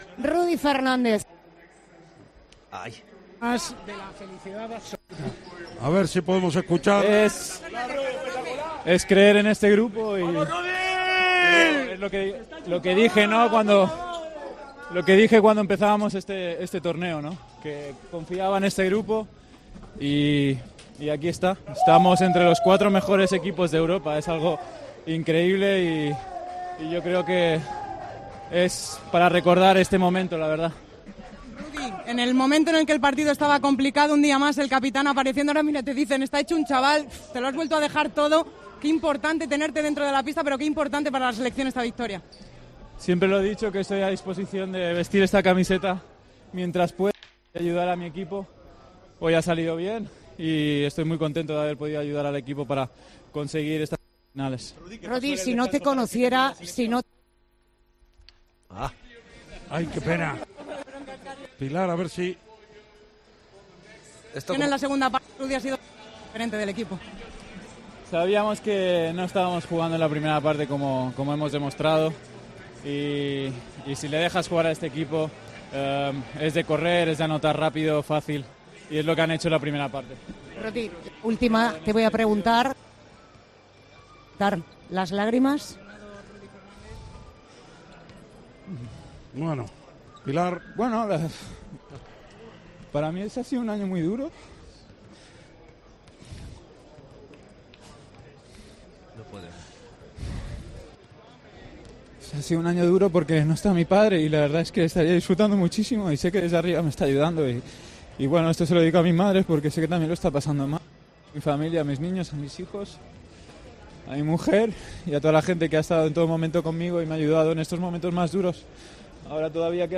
Rudy Fernández, capitán de la Selección de baloncesto, ha analizado muy emocionado la victoria contra Finlandia en los cuartos de final del Eurobasket. "Se que me está ayudando desde arriba", ha señalado entre lágrimas recordando a su padre fallecido.